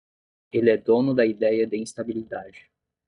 /ĩs.ta.bi.liˈda.d͡ʒi/